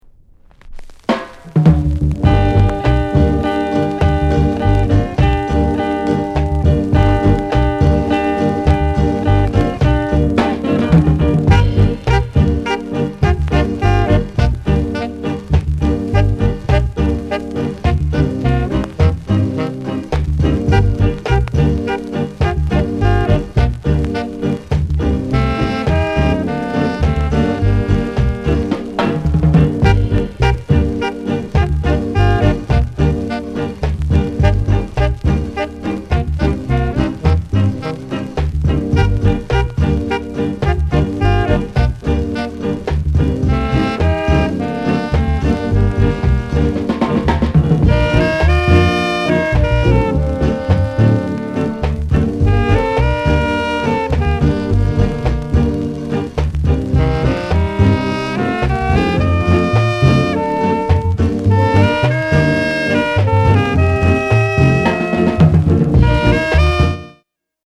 SOUND CONDITION A SIDE VG-(OK)
NICE ROCKSTEADY